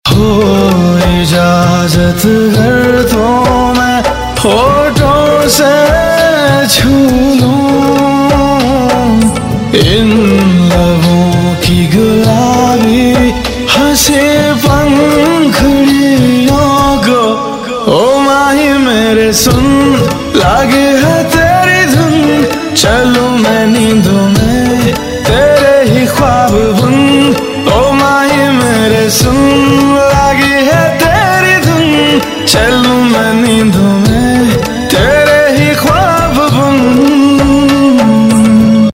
Pop Ringtones